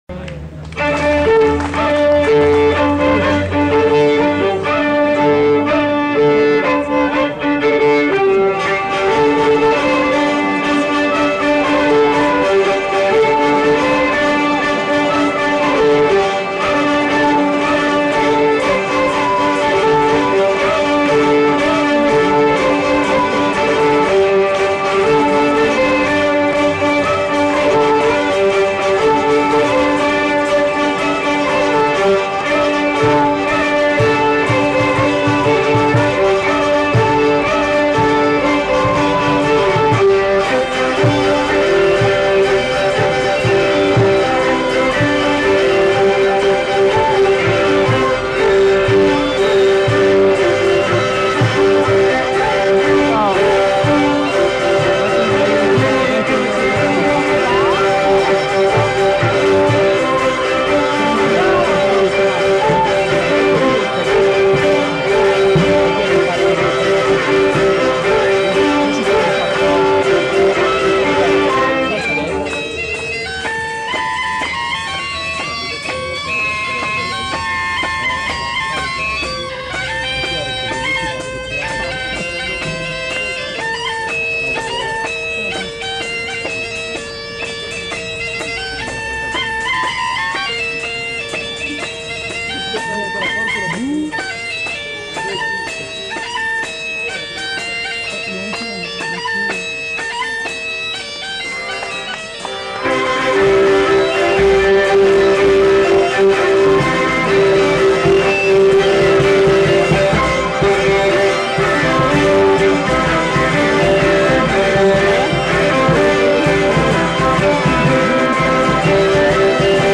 Aire culturelle : Pays d'Oc
Genre : morceau instrumental
Instrument de musique : violon ; vielle à roue ; boha
Danse : saut du lapin
Notes consultables : Deux airs enchaînés.